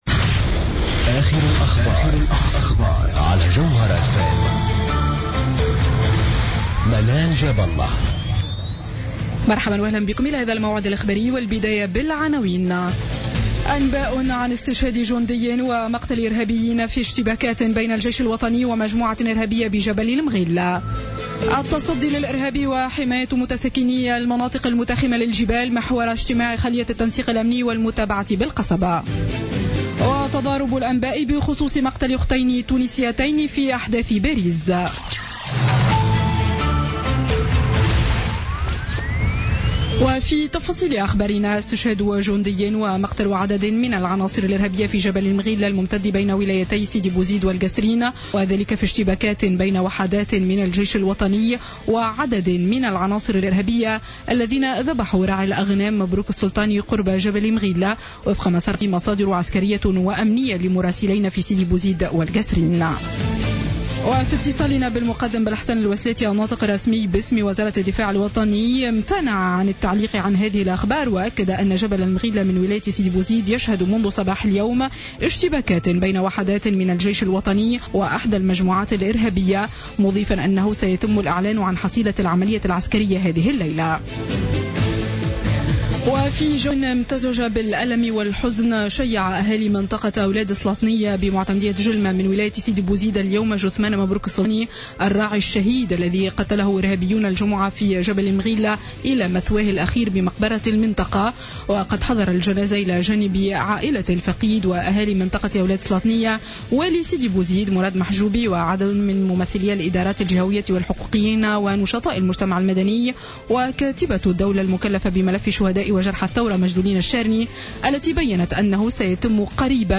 نشرة أخبار السابعة مساء ليوم الأحد 15 نوفمبر 2015